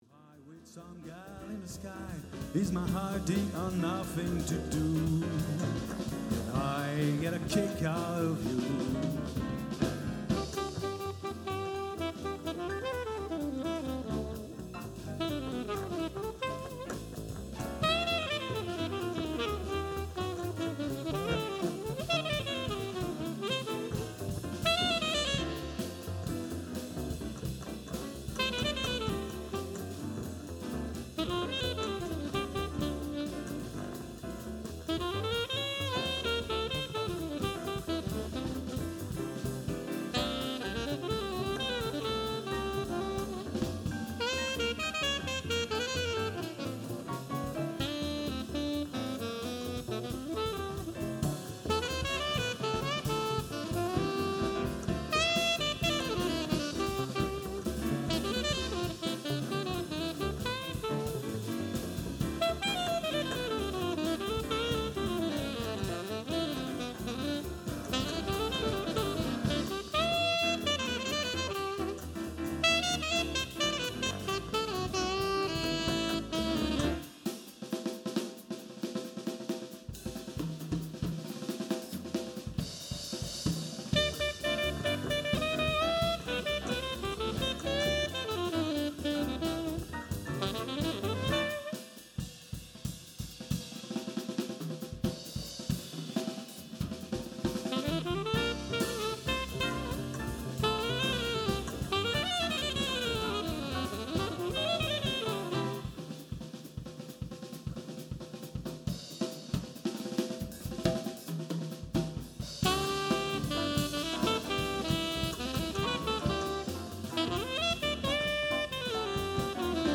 un petit extrait d'un concert que j'ai sonorisé mardi dernier (comme souvent j'enregistre un témoin en sortie de console pour "voir" ensuite ce que j'ai fait...)
tout est fait dans la 01V96 sans périphes externe et sans aucune compression sur les sources (le piano était un truc électrique bon marché; j'ai fait ce que j'ai pu mais le son n'est pas terrible); la batteuse est prise avec 3 mics; la Co-Ba avec capteur Schertler est pris direct sur l'ampli pas avec un mic (le musicien le voulait ainsi)
comme c'est du Jazz j'ai mixé assez cool (vers -17dB); mon enregistrement d'origine (donc sortie mix console via SPDIF sur une carte flash) sonne comme ça :
jazz_org.mp3